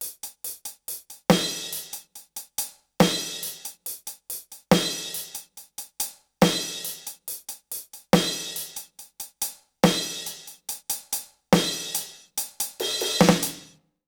British ROCK Loop 137BPM (NO KICK).wav